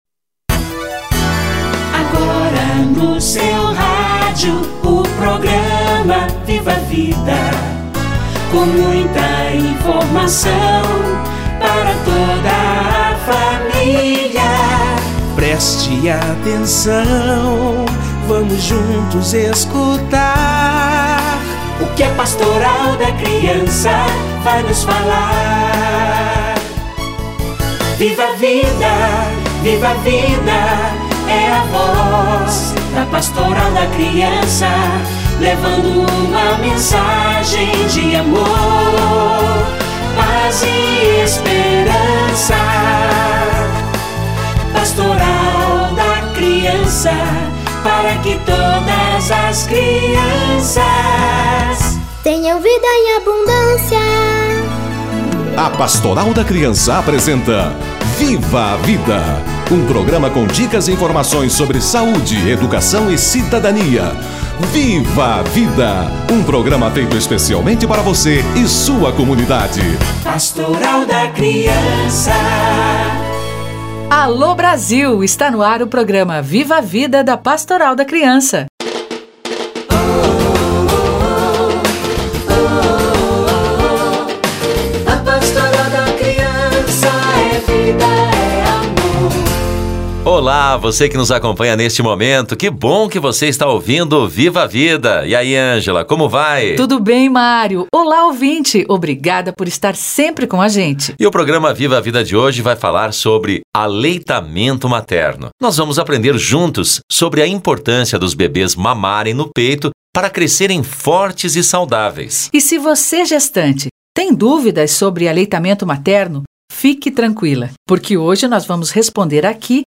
Aleitamento materno - Entrevista